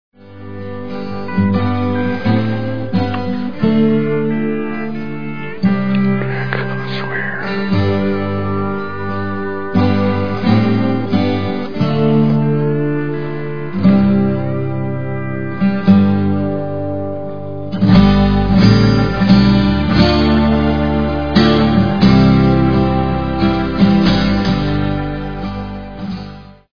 Music: Theme song from score.